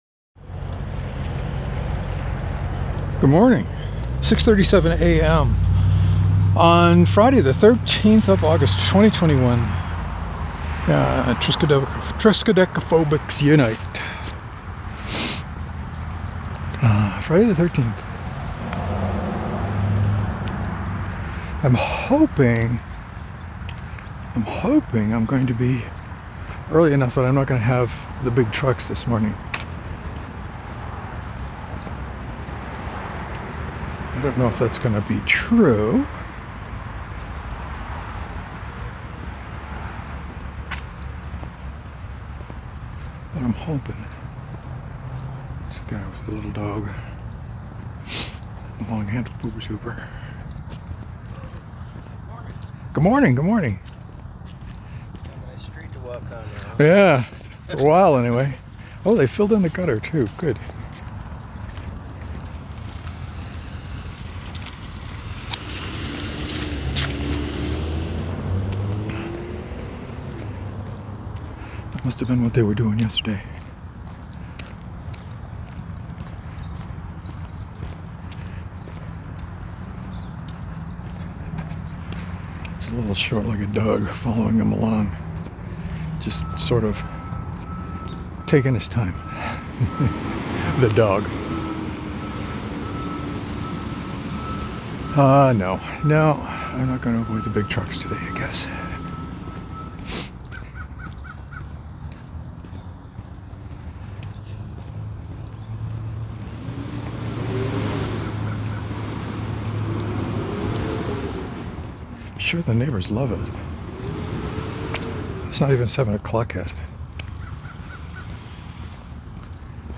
I had a hard time staying on any kind of topic this morning. There are trucks – not as many as yesterday – but no wind.